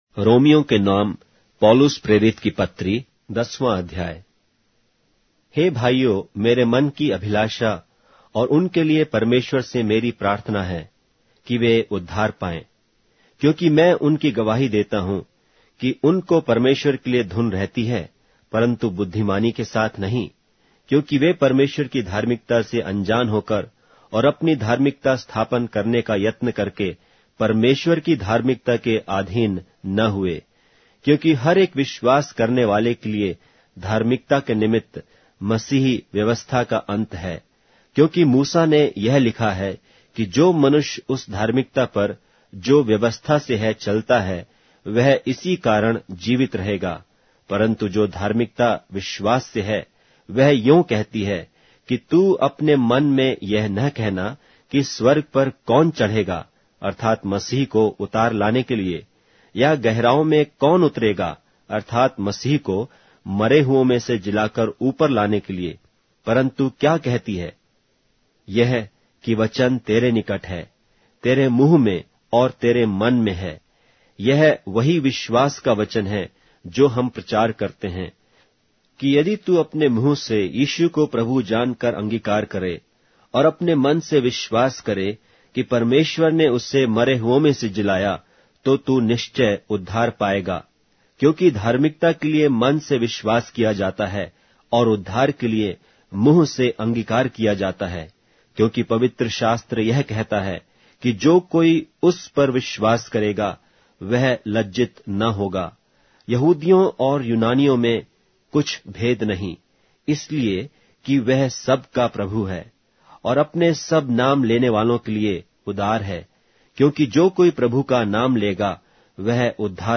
Hindi Audio Bible - Romans 15 in Ylt bible version